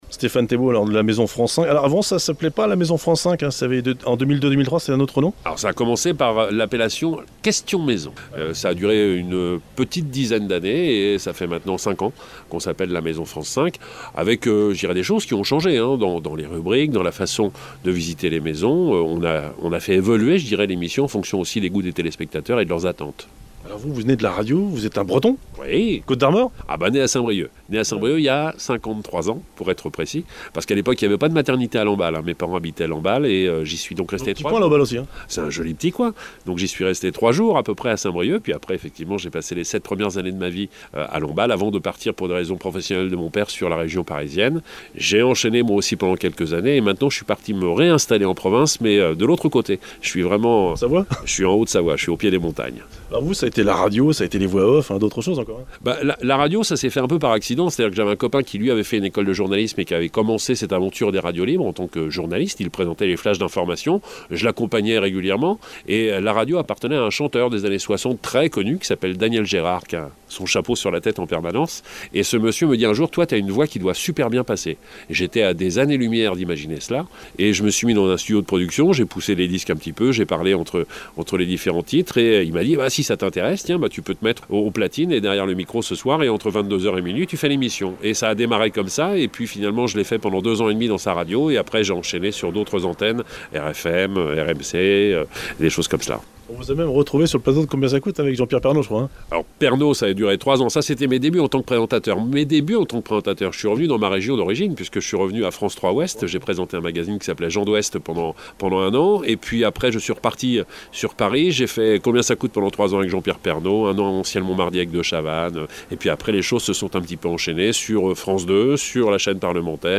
Interview de Stéphane Thébaud – Présentateur et animateur de « La Maison France 5″, une émission de qualité !